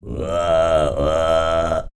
TUVANGROAN14.wav